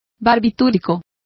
Complete with pronunciation of the translation of barbiturate.